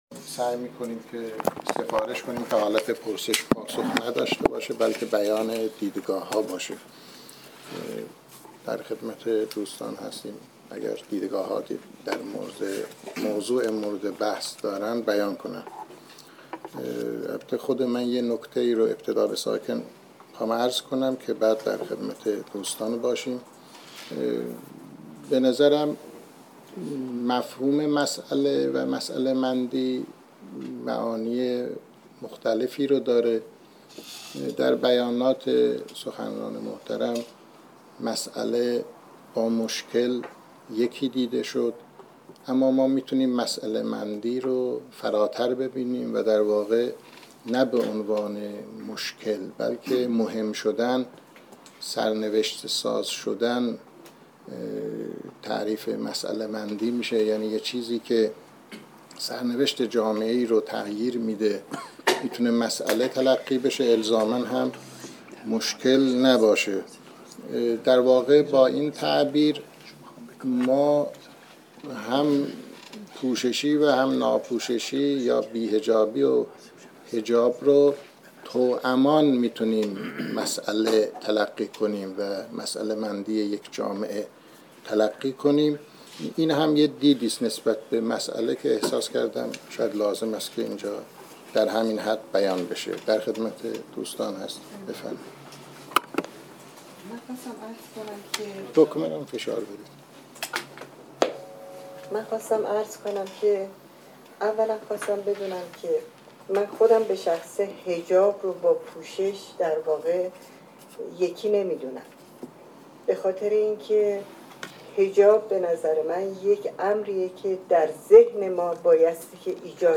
اولین نشست علمی پژوهشکده را برگزار می کند: